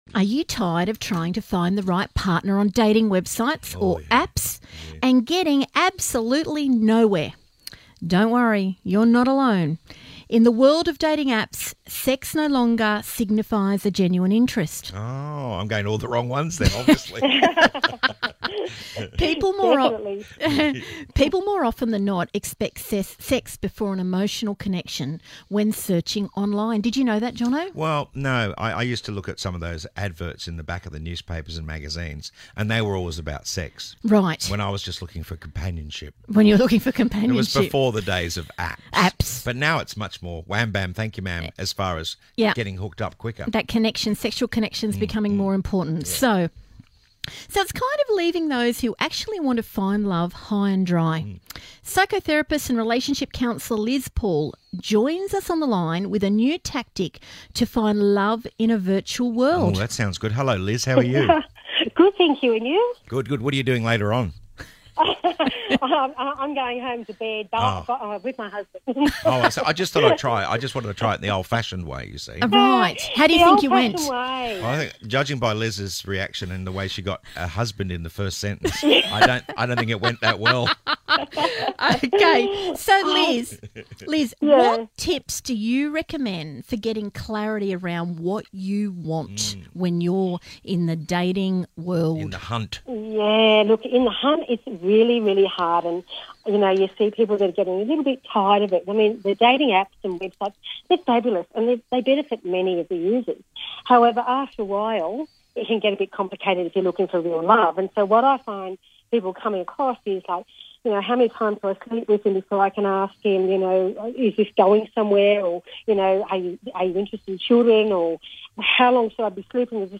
Radio interview: Tired of trying to find love online and getting absolutely nowhere?